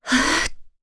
Veronica-Vox_Casting1_jp.wav